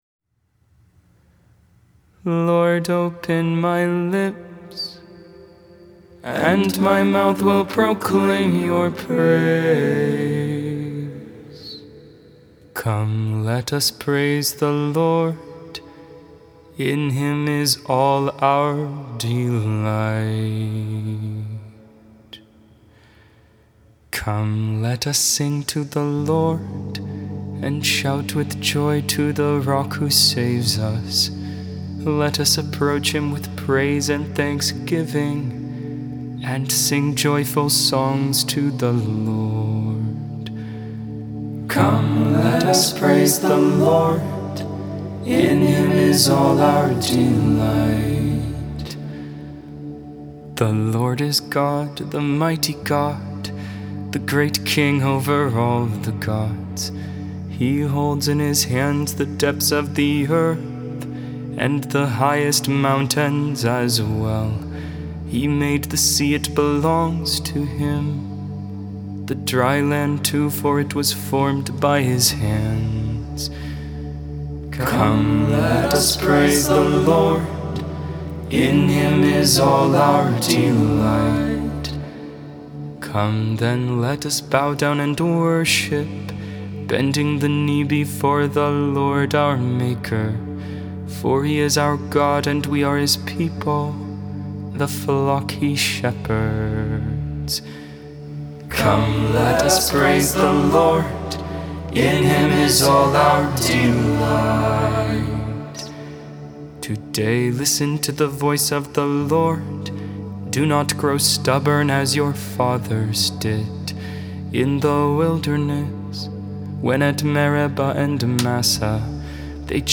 Psalm 51 (tone 7)